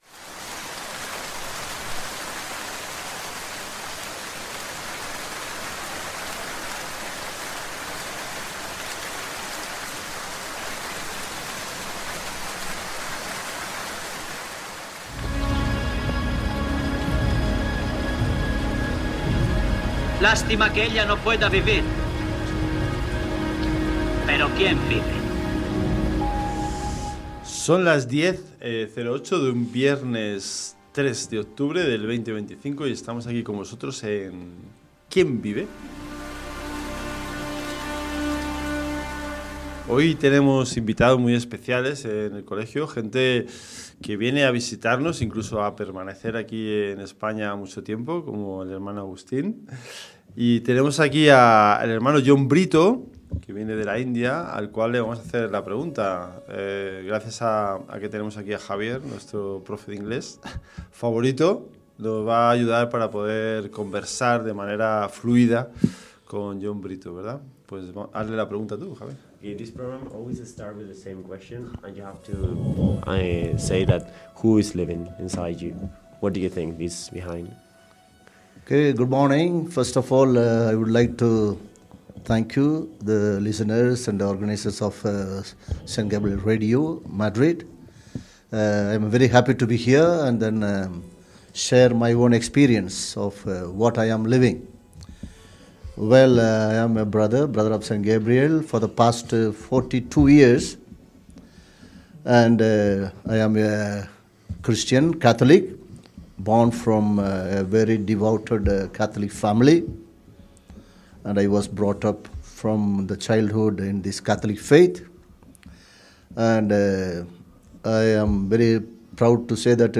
Hoy nos visitan dos hermanos gabrielistas: